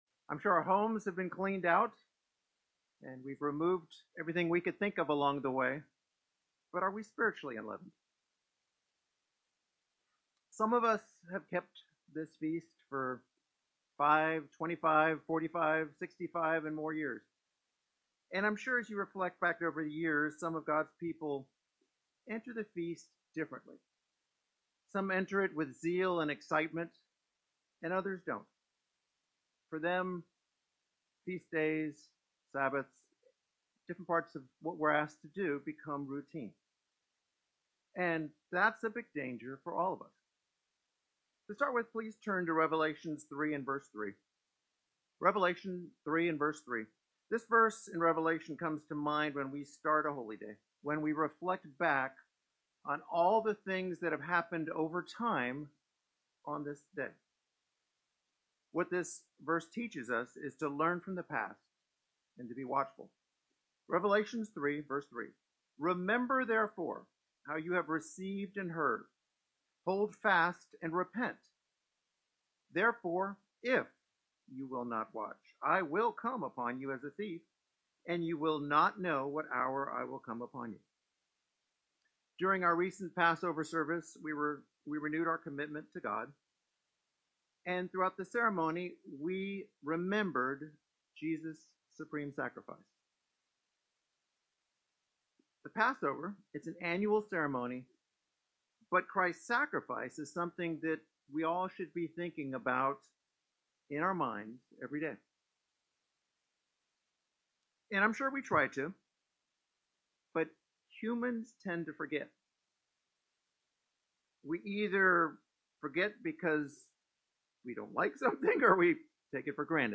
This sermon shows that God wants us to become holy as He is holy.